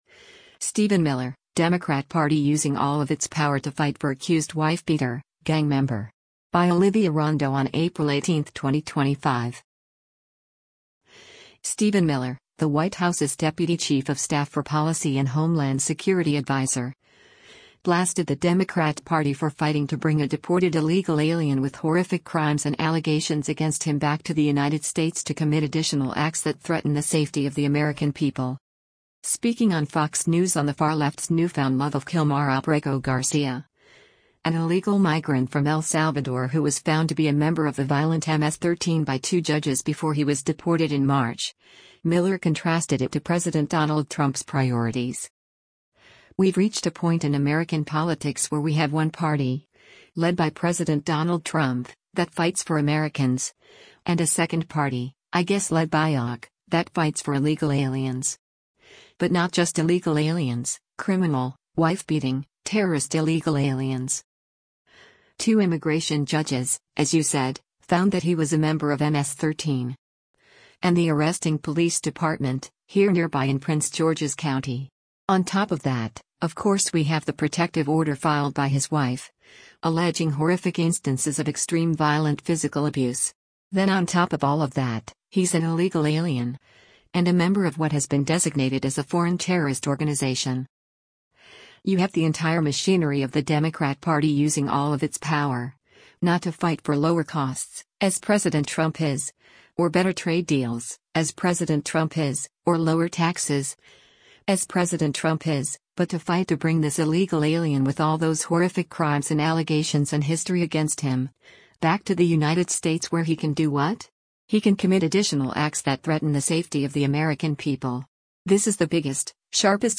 White House Deputy Chief of Staff Stephen Miller speaks to press outside of the White Hous
Speaking on Fox News on the far-left’s newfound love of Kilmar Abrego Garcia, an illegal migrant from El Salvador who was found to be a member of the violent MS-13 by two judges before he was deported in March, Miller contrasted it to President Donald Trump’s priorities: